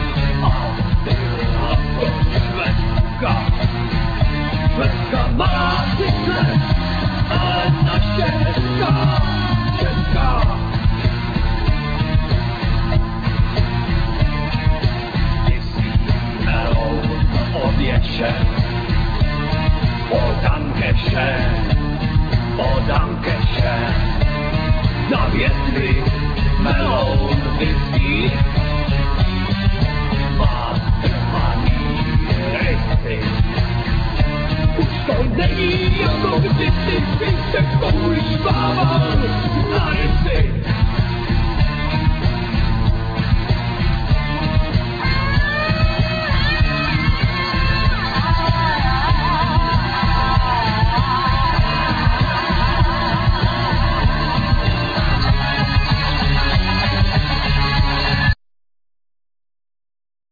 Vocals,Guitar
Bass,Vocals
Drums
Percussions
Tennor saxophone,Vocals